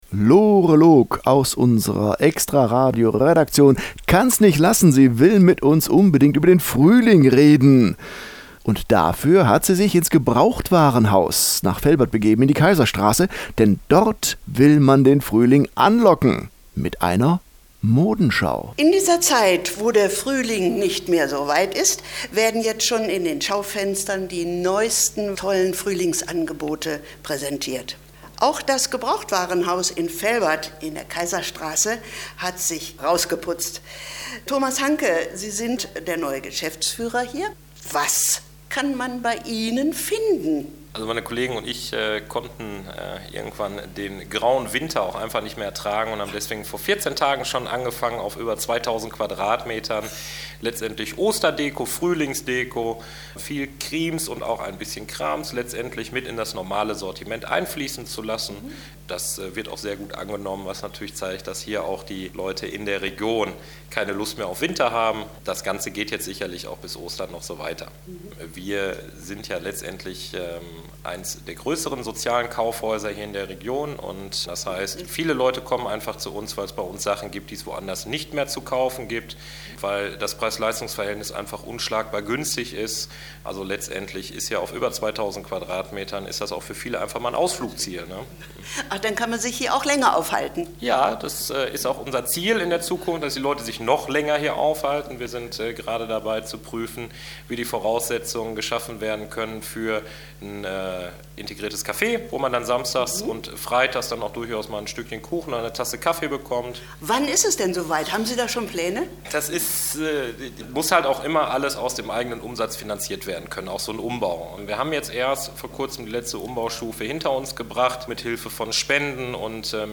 GbwModenschauKomplett.mp3